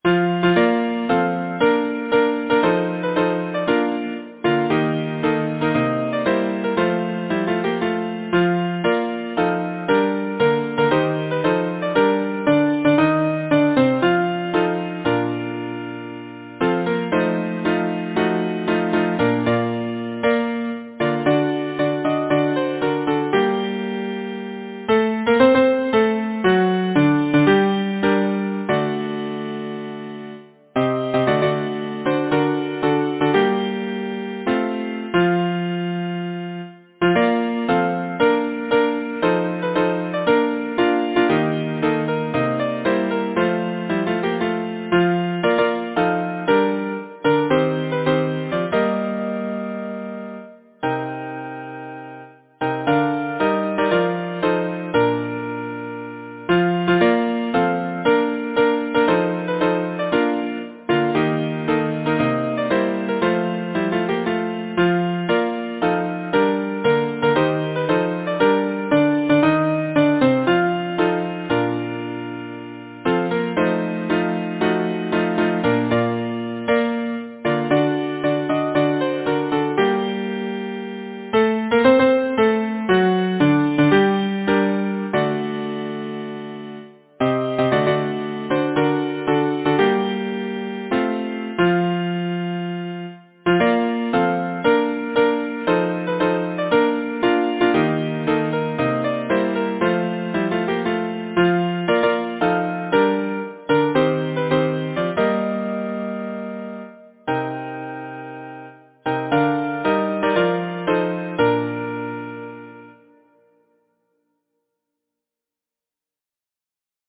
Title: The King of the Southern Sea Composer: Nathan James Sporle Lyricist: Joseph Edwards Carpenter Number of voices: 4vv Voicing: SATB Genre: Secular, Partsong
Language: English Instruments: A cappella